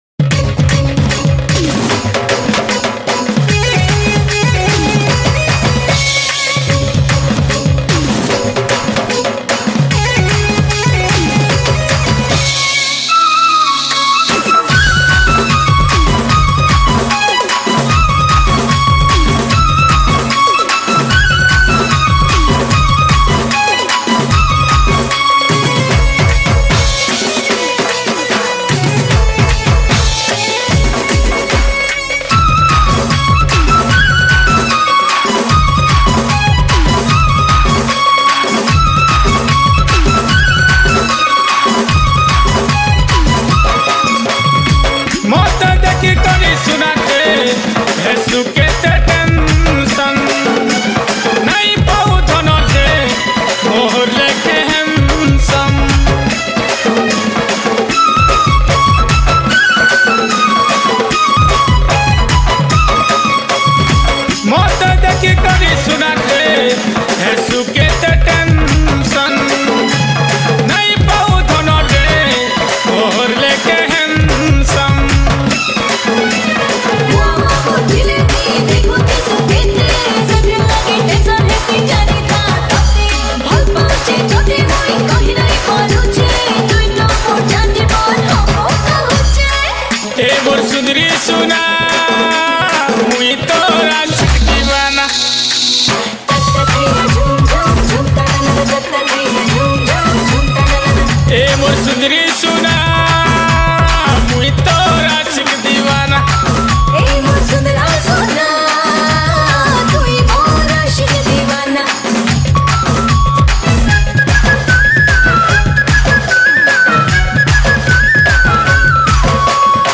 New Sambalpuri Song mp3 Song Download